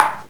drum-hitwhistle.ogg